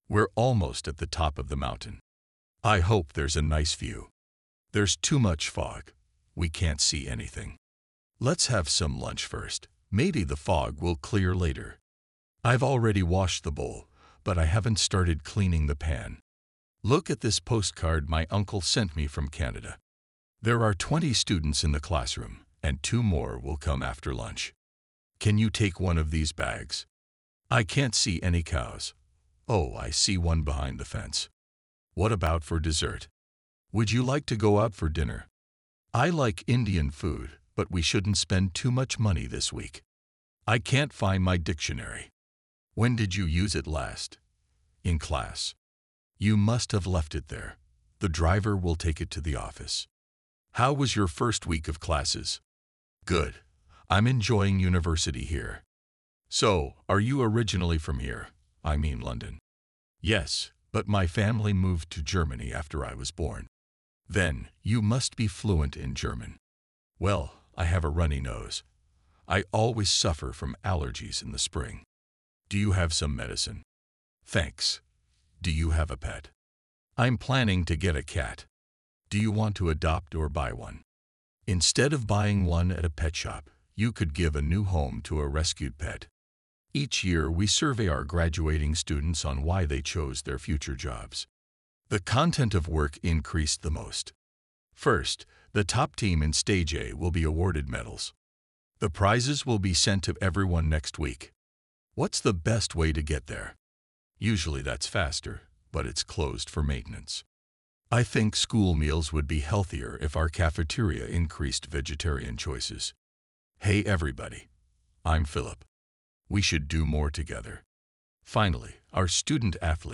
このコーナーでは、2025年共通テスト英語リスニング対策として、過去2024年、2023年の本試験と追試験のリスニングスクリプトから、超重要＆頻出の対話文や英文スクリプトを100本抽出し、イケボに読んでもらいました！